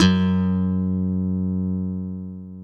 KW FUNK  F#2.wav